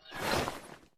heavy_draw.ogg